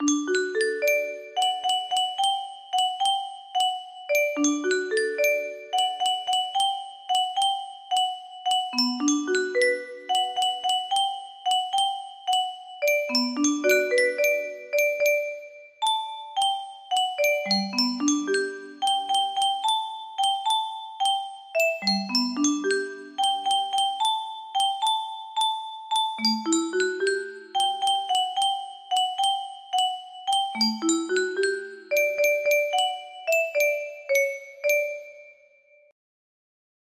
test 1 music box melody